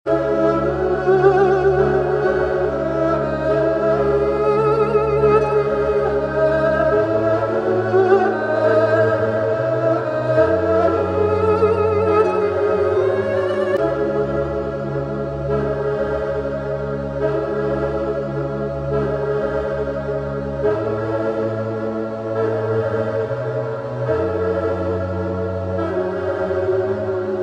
Hip HopTrap
合成器和乐器一枪的集合模拟了VST的功能，而无需担心高价软件。
Drill具有非常规的鼓弹和沉重的808s滑动功能，与通常的陷井拍相比，它以不同的方式吸引了听众的注意力。
RnB波浪形的和弦进程和充满灵魂的嗓音将多种流派无缝地融合在一起。